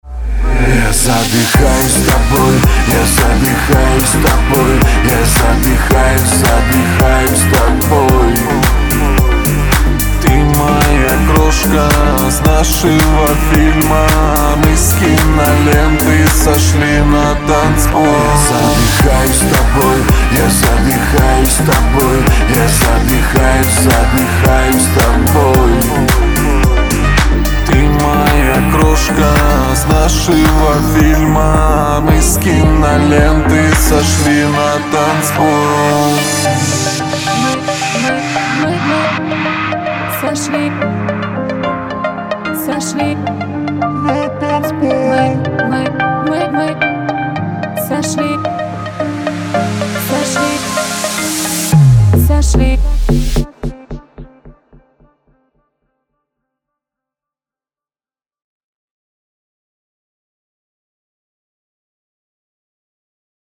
• Качество: 320, Stereo
поп
мужской вокал
громкие
dance
club